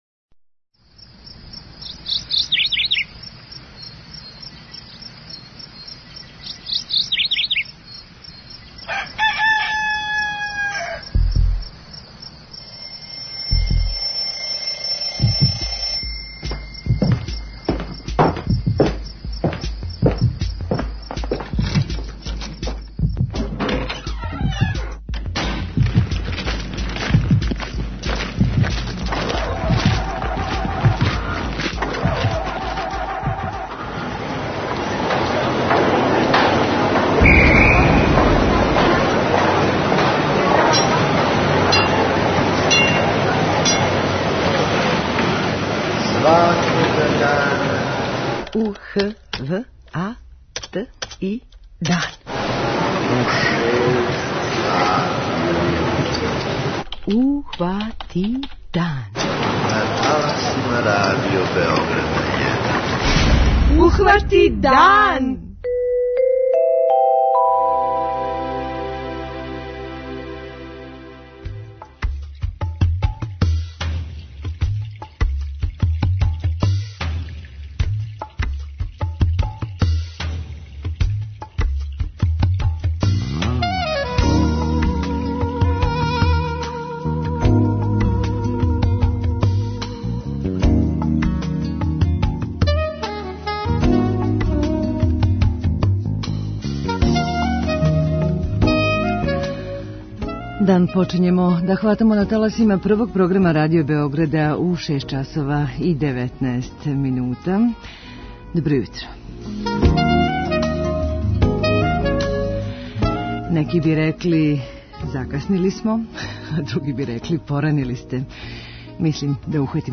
06:30 Догодило се на данашњи дан, 07:00 Вести, 07:05 Добро јутро децо, 08:00 Вести, 08:05 Српски на српском, 08:15 Гост јутра